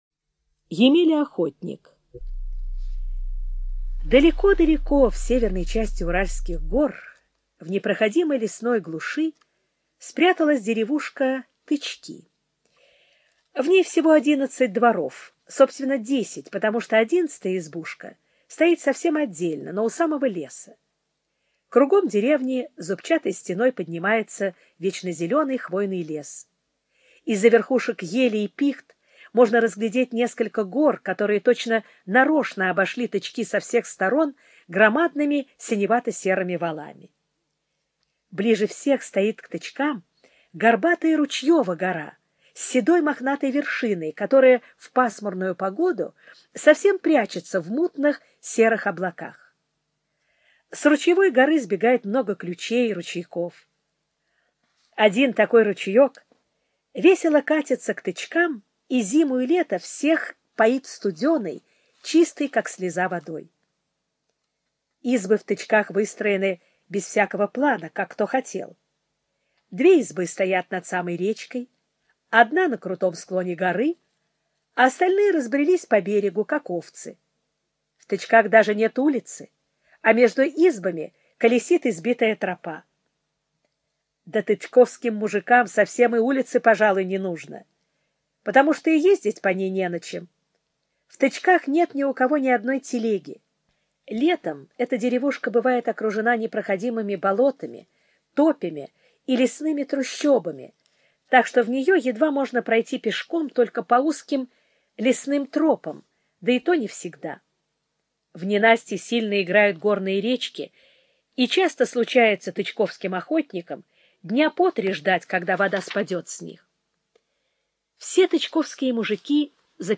Емеля-охотник - аудио рассказ Мамина-Сибиряка - слушать онлайн